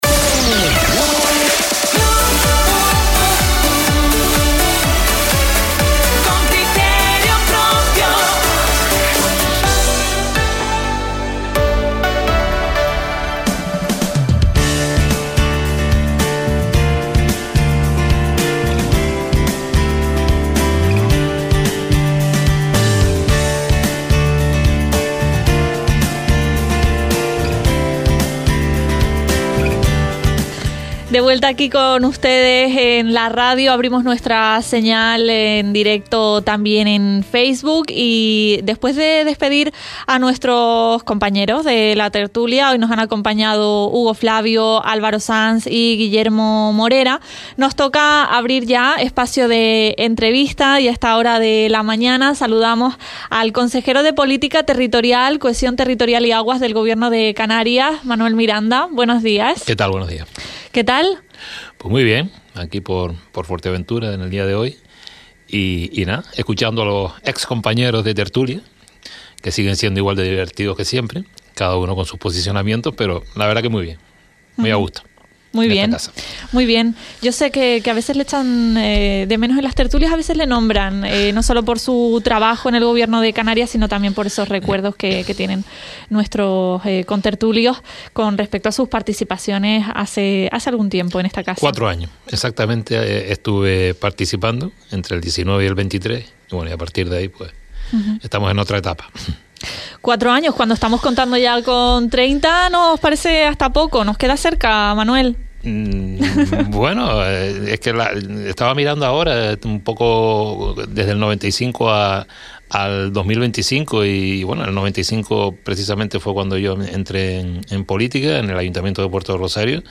Entrevista a Manuel Miranda, consejero de Política Territorial, Cohesión Territorial y Aguas del Gobierno de Canarias – 19.06.25